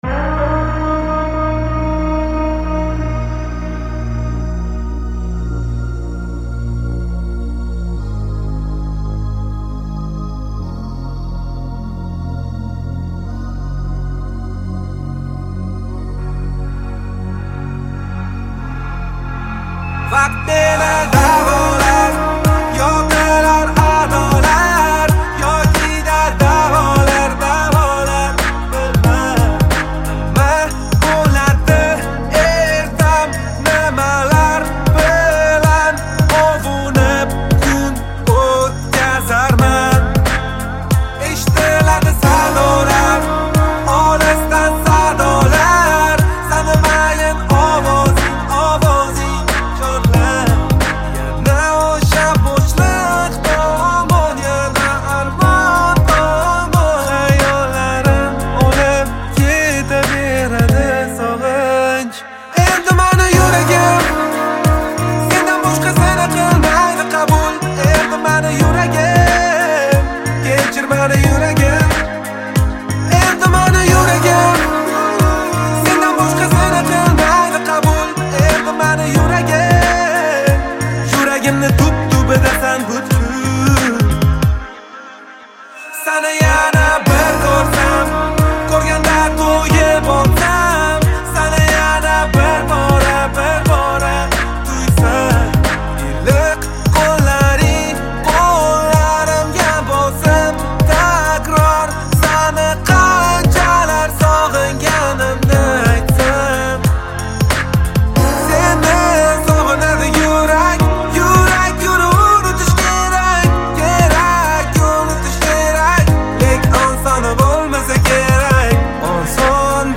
Жанр: Узбекская музыка